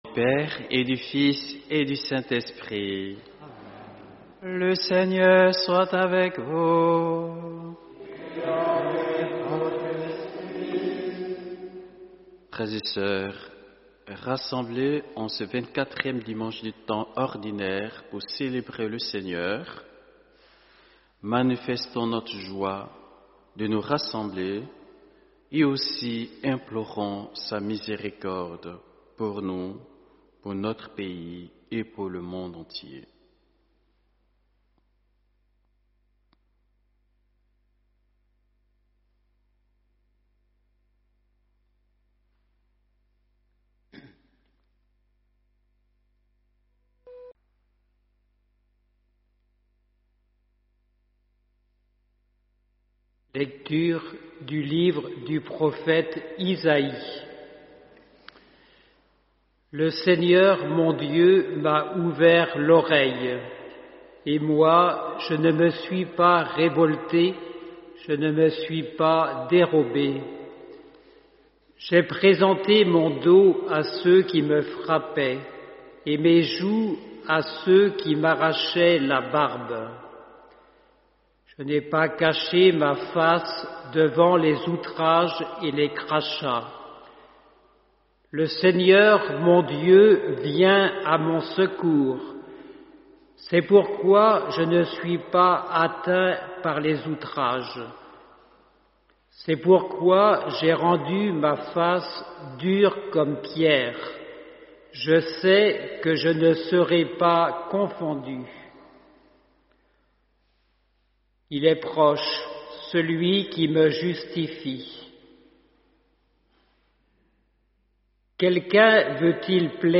Homélie : Frères et sœurs, est-ce que vous allez bien ce matin ?